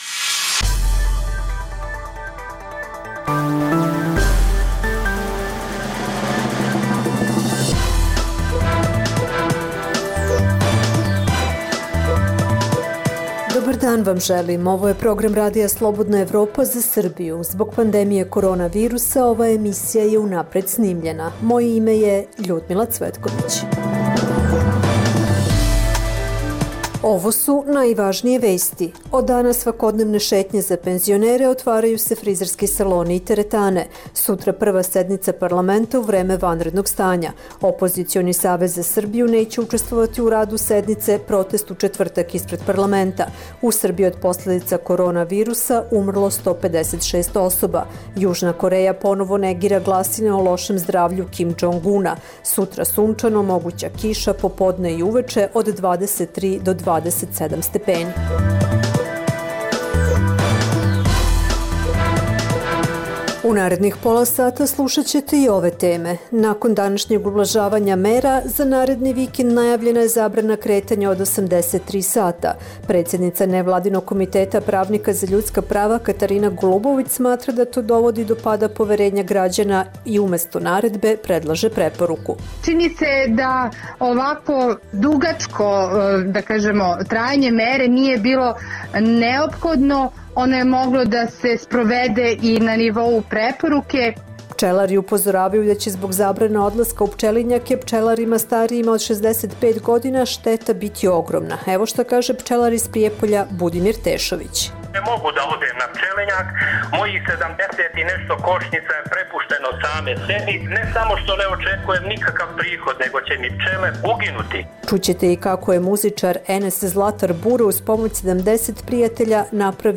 Zbog pandemije korona virusa ova emisija je unapred snimljena. Od danas svakodnevne šetnje za penzionere, otvaraju se frizerski saloni i teretane.